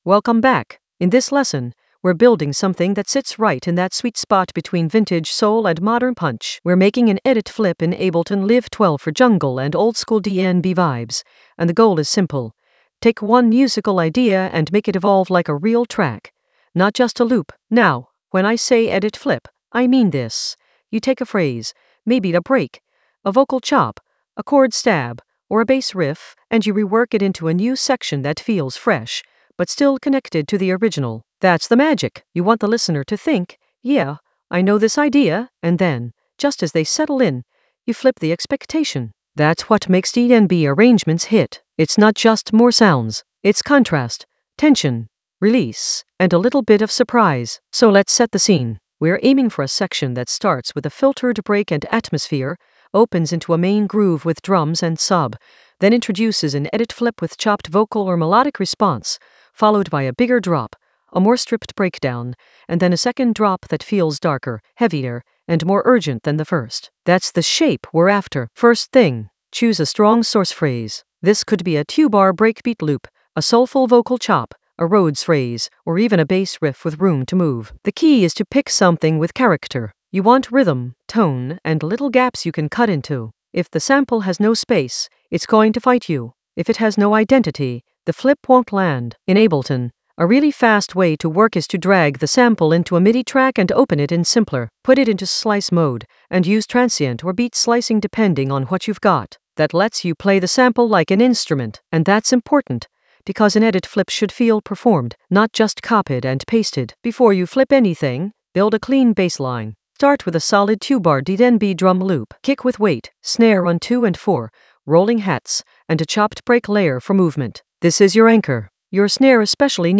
An AI-generated intermediate Ableton lesson focused on Edit flip course with modern punch and vintage soul in Ableton Live 12 for jungle oldskool DnB vibes in the Arrangement area of drum and bass production.
Narrated lesson audio
The voice track includes the tutorial plus extra teacher commentary.